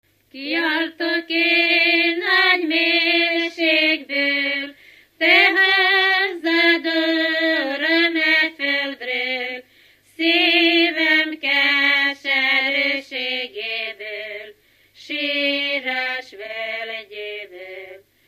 Dunántúl - Verőce vm. - Lacháza
Stílus: 4. Sirató stílusú dallamok